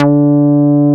P MOOG D4F.wav